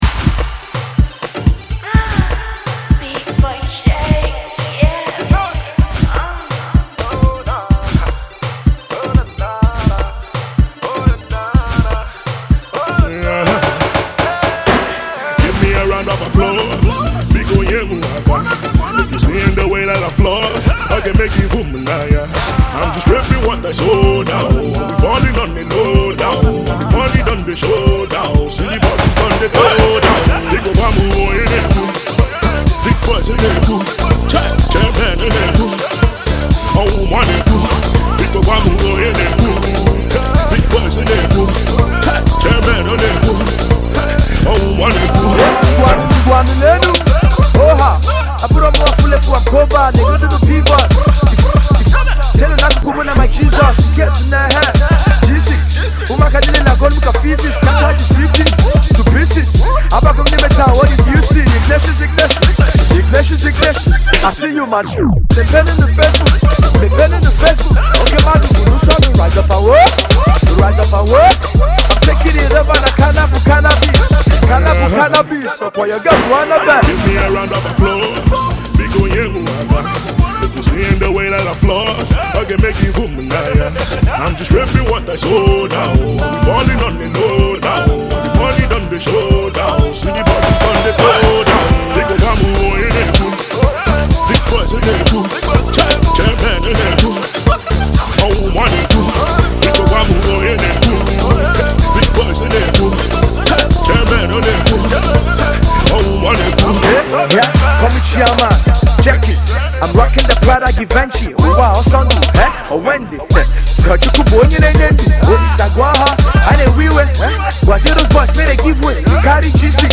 Alternative Rap